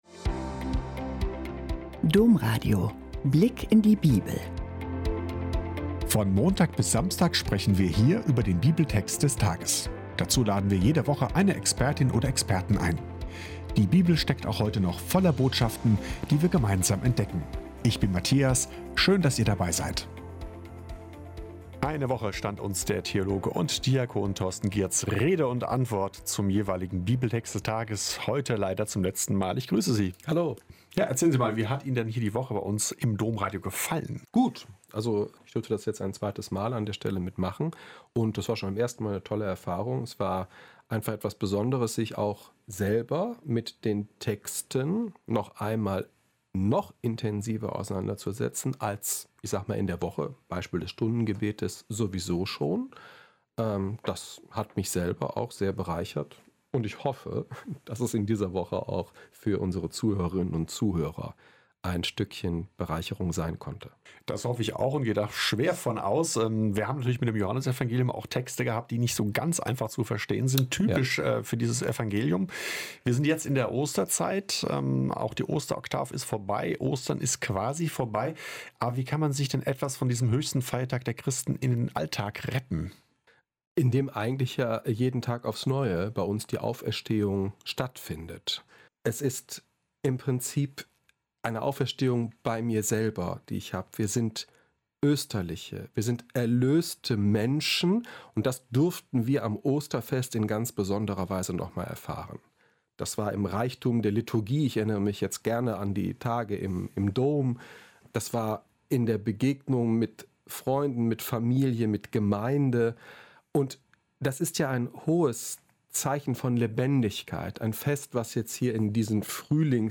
Lassen wir Jesus ins Boot? - Gespräch